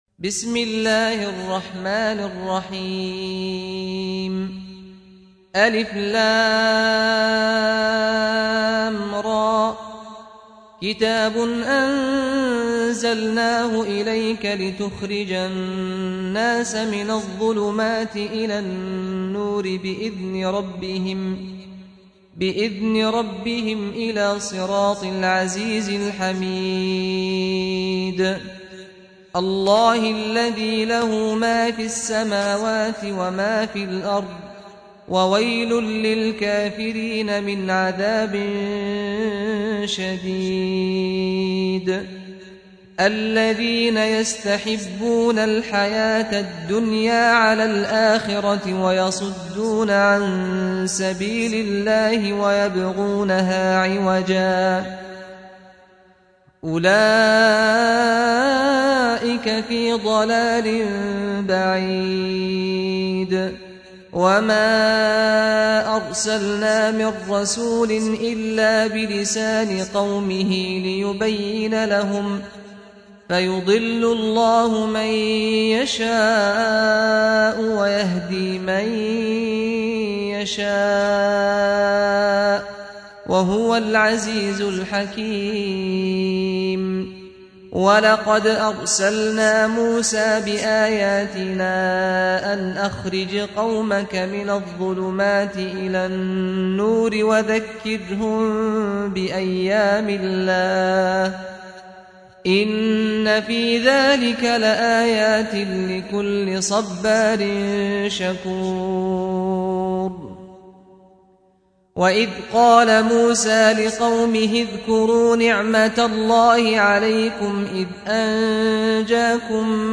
سُورَةُ ابراهيم بصوت الشيخ سعد الغامدي